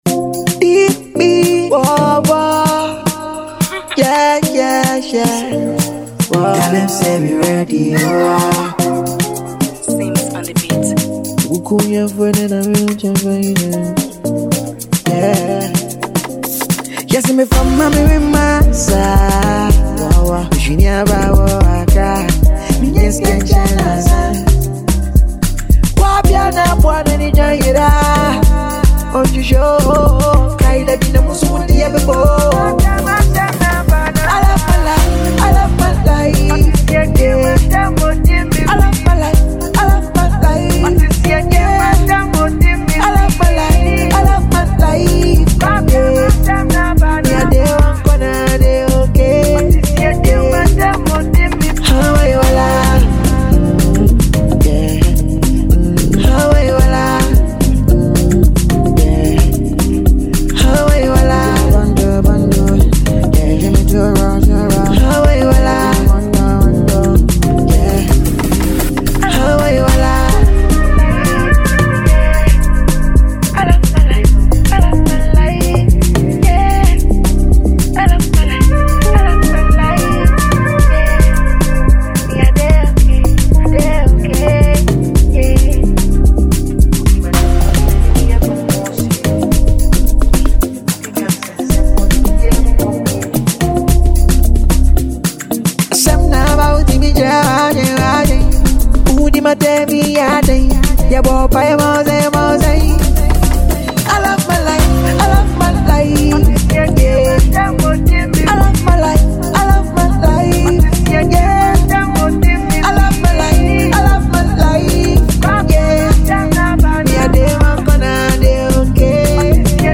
This Amapiano track is sure to get you moving and grooving.